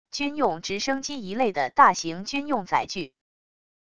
军用直升机一类的大型军用载具wav音频